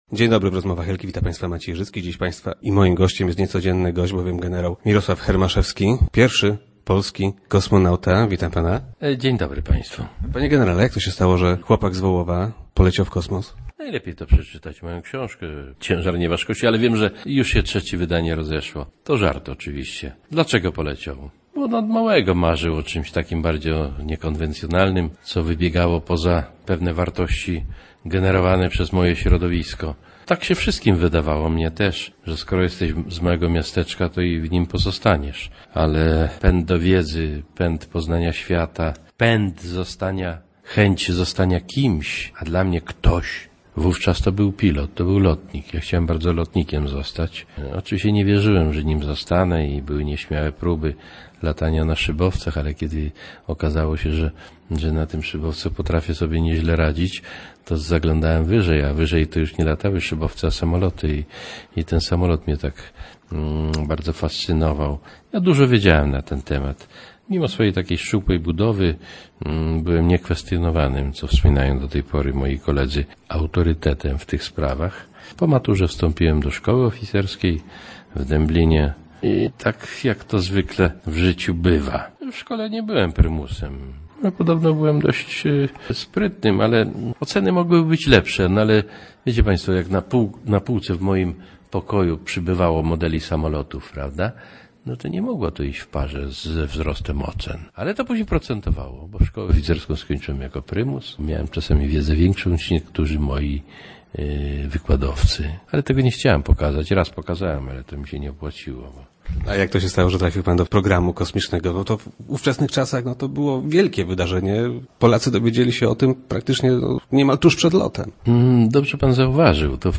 Jak to się stało, że chłopak z Wołowa trafił na okołoziemską orbitę? - Od dziecka miałem niekonwencjonalne marzenia - twierdzi generał Hermaszewski, który był gościem Rozmów Elki.